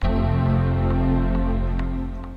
Play, download and share Mac startup for iPhone original sound button!!!!
mac-startup-for-iphone.mp3